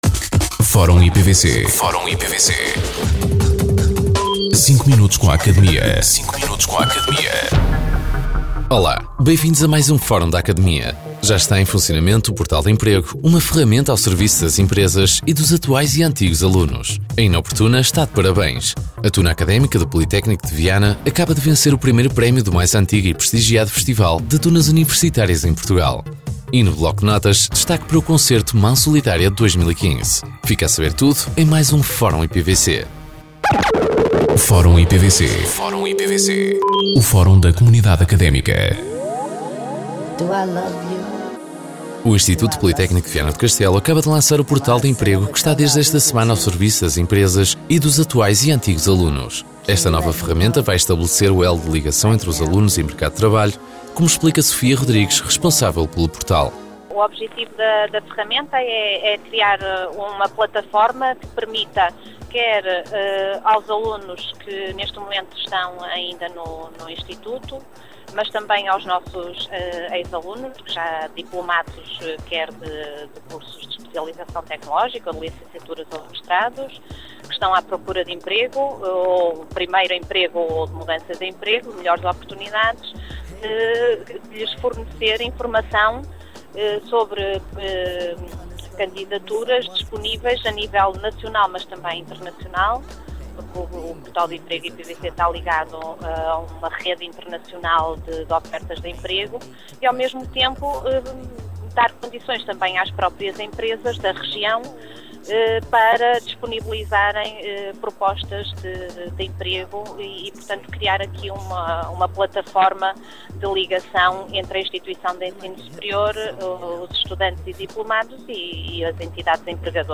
Depois do programa “Academia”, surge assim o “Fórum IPVC”, uma rubrica semanal de cinco minutos, em que são abordadas as notícias e factos mais relevantes da nossa instituição.
Entrevistados: